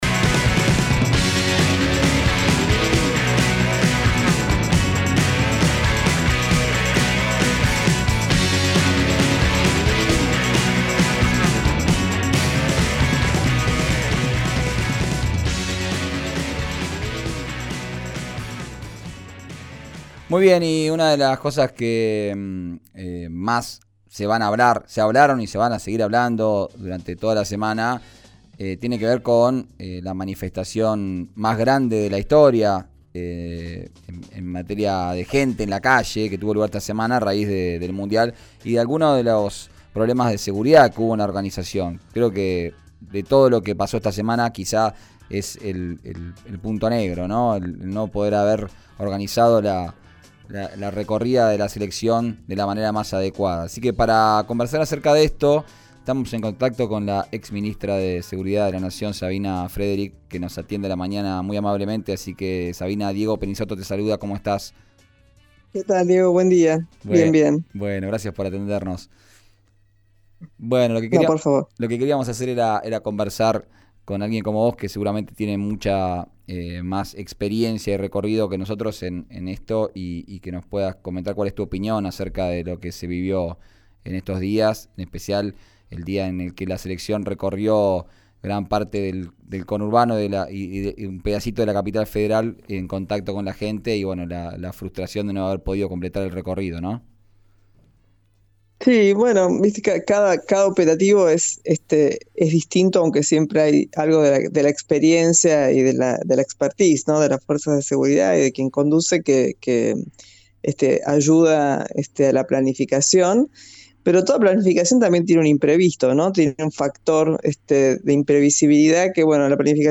Sabina Frederic habló del operativo para recibir a la Selección Argentina, en 'Arranquemos' por RÍO NEGRO RADIO.